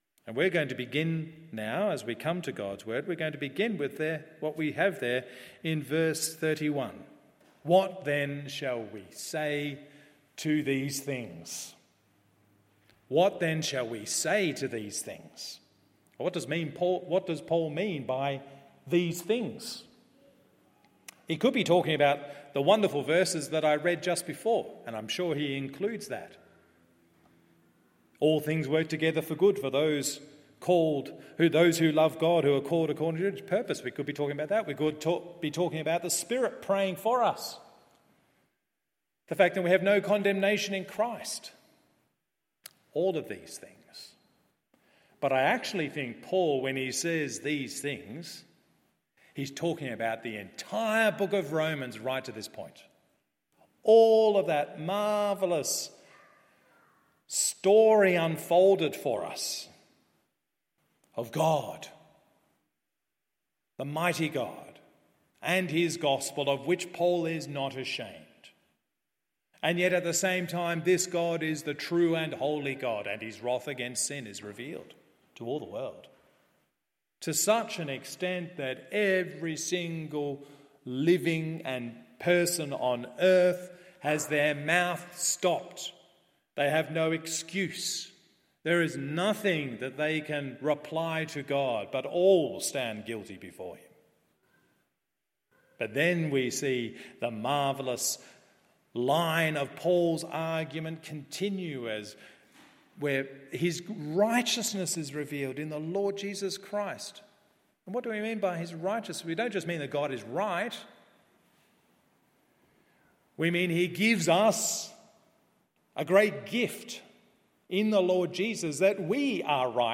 MORNING SERVICE Romans 8:26-39…